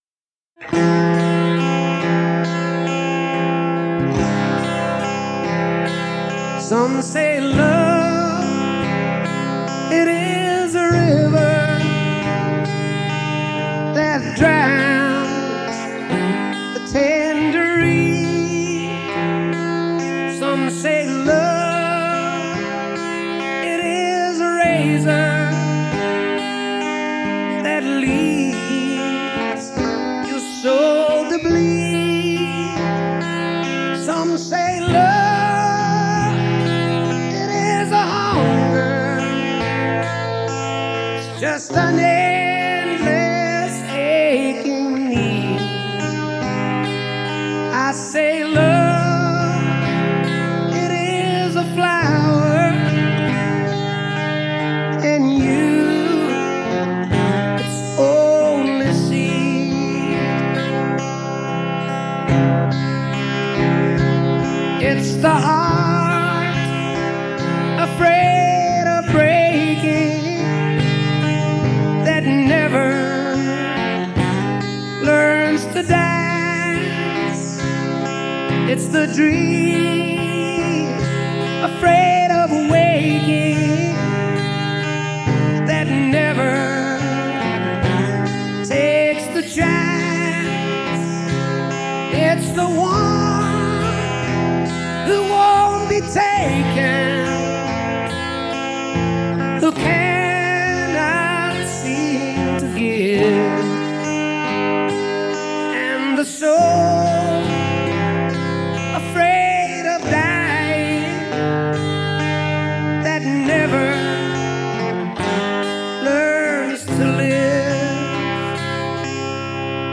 Rock.